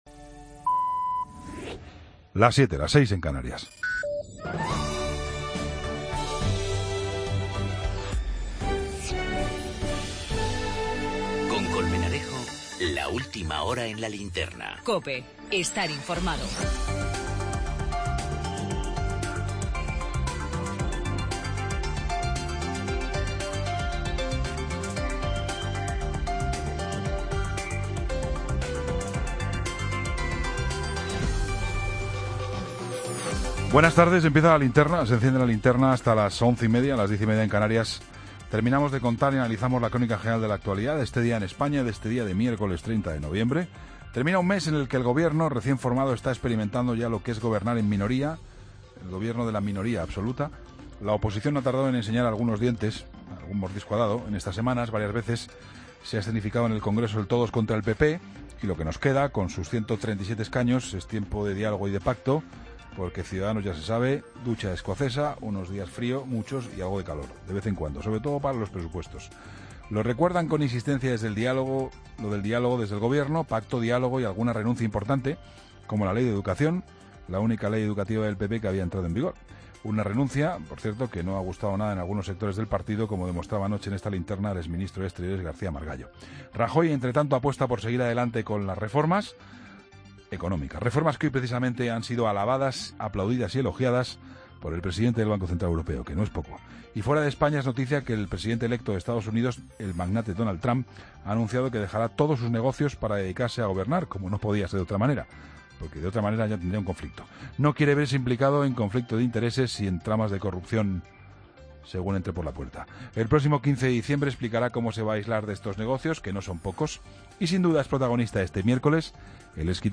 AUDIO: Toda la información con Juan Pablo Colmenarejo.